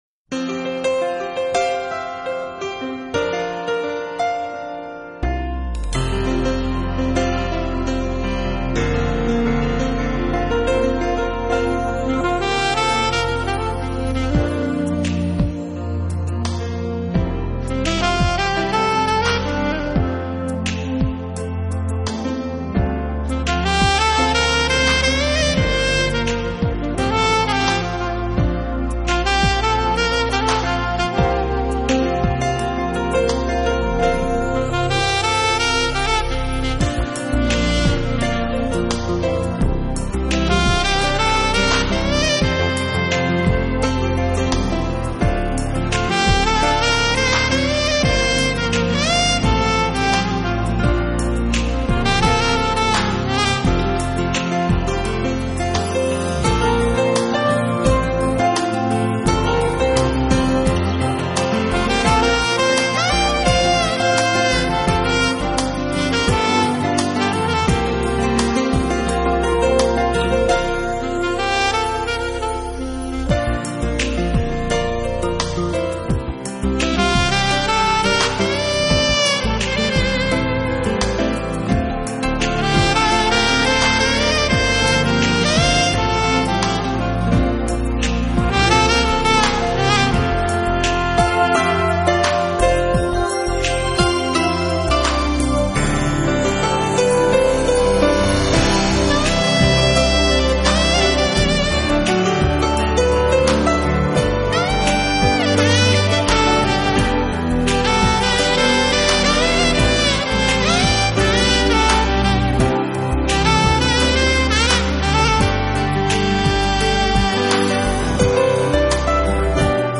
听他的琴声，你会感受到如水般的魅惑，他的琴音，轻爽而柔软，